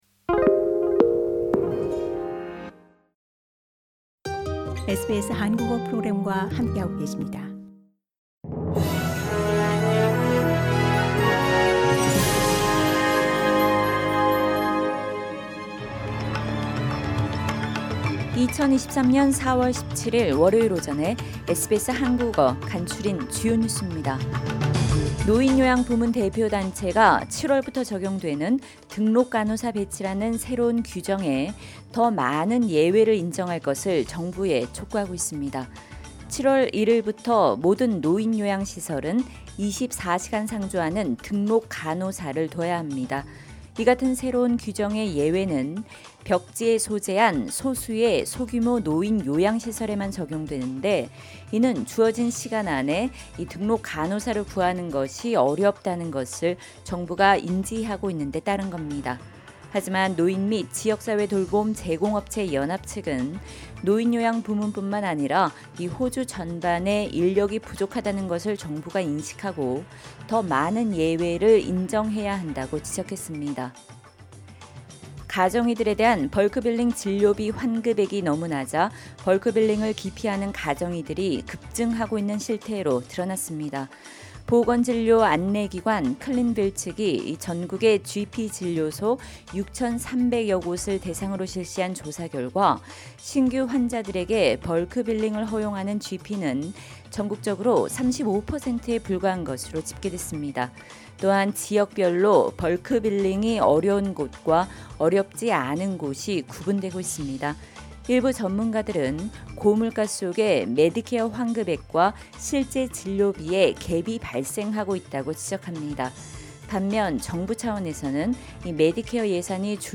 2023년 4월 17일 월요일 아침 SBS 한국어 간추린 주요 뉴스입니다.